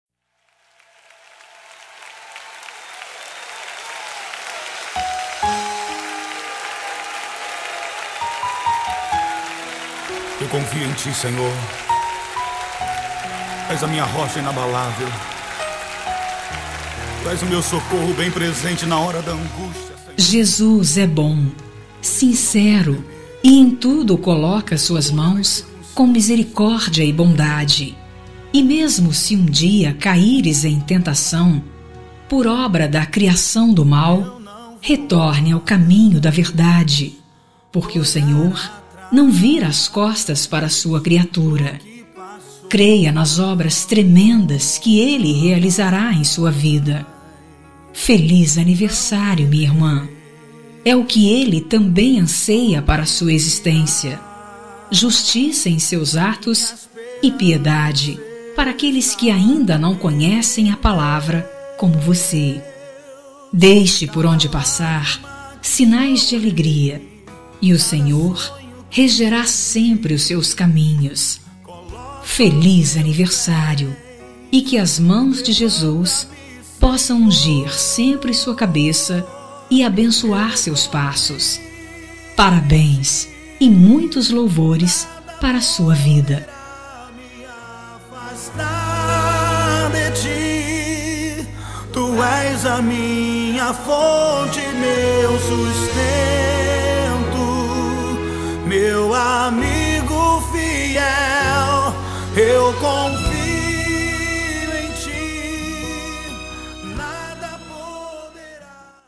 Telemensagem Aniversário de Irmã – Voz Feminina – Cód: 20227 – Evangélica
03- IRMÃ GOSPEL FEM 1.mp3